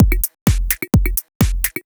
Electrohouse Loop 128 BPM (19).wav